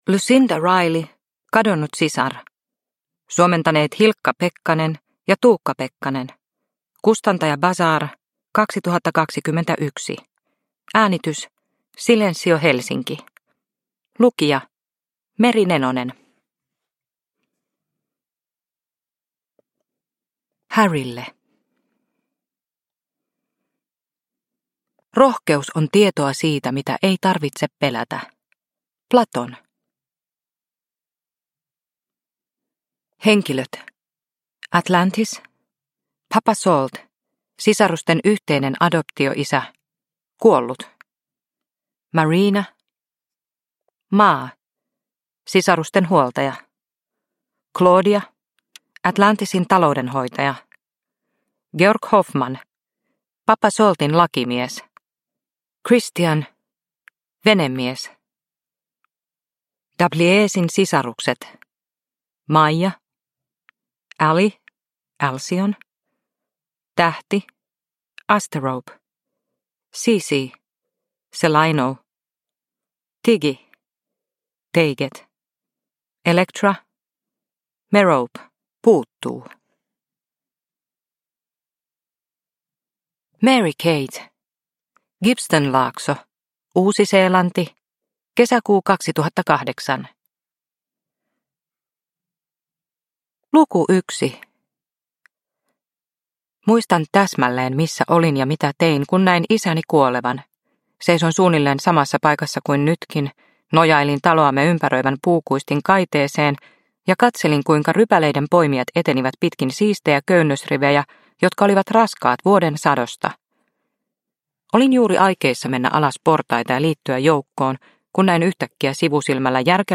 Kadonnut sisar – Ljudbok – Laddas ner